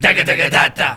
All Punjabi Vocal Pack